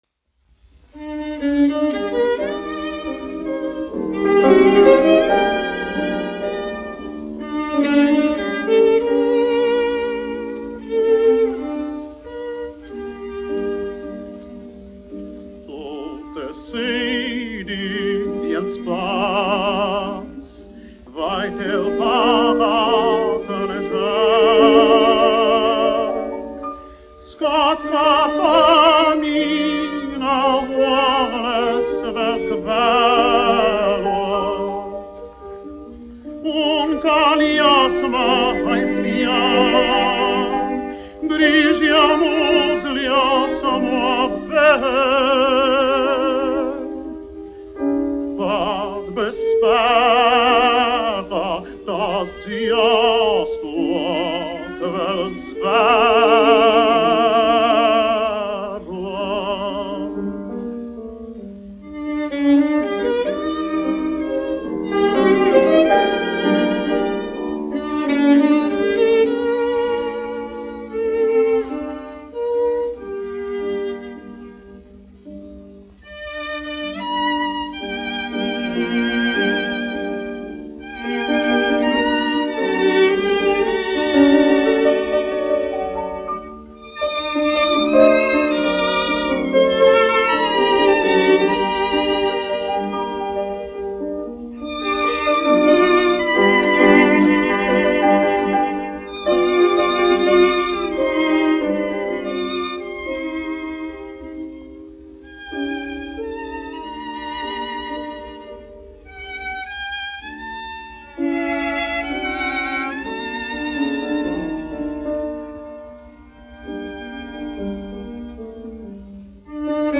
1 skpl. : analogs, 78 apgr/min, mono ; 25 cm
Krievu dziesmas
Skaņuplate